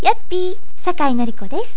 「ヤッピー！」（のりピー、yappi-.wav２０ｋｂ）
どう？やっぱり「ヤッピー！」って、言ってるよね？